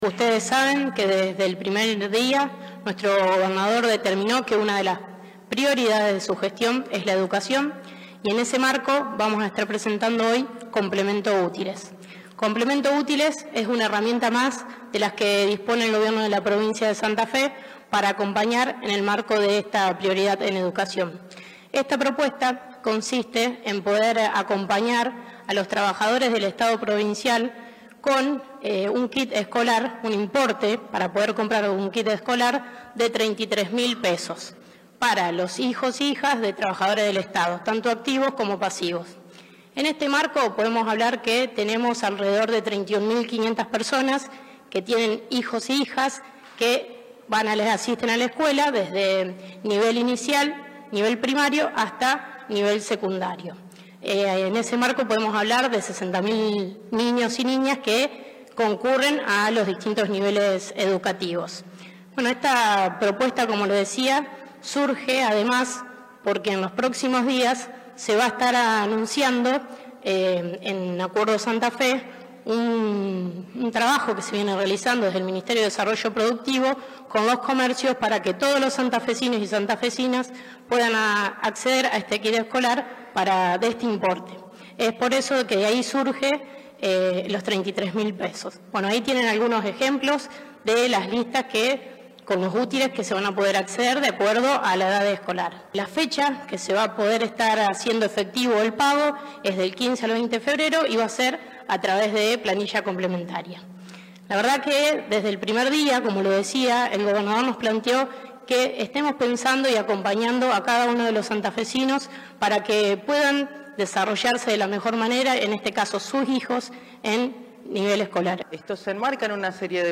Fragmentos de la presentación, a cargo de Tejeda y Martín